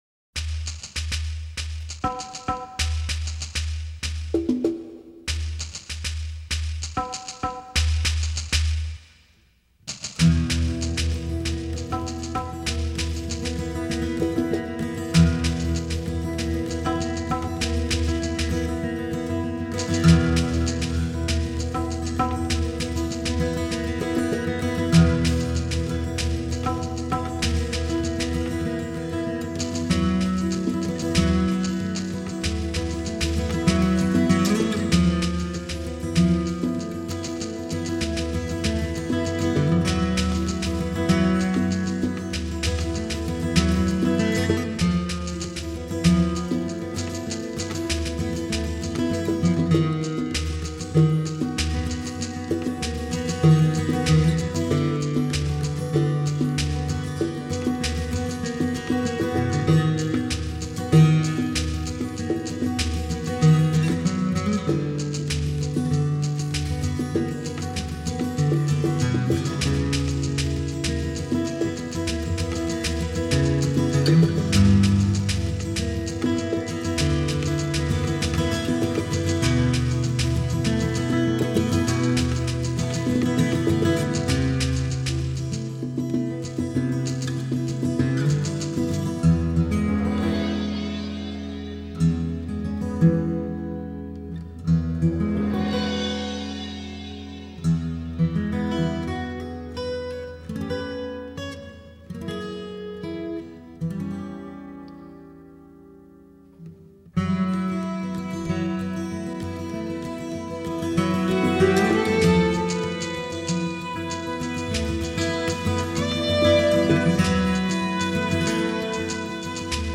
Guitar, Composer
Violin
Soprano & Alto Sax
Electric Bass
Drums, Percussion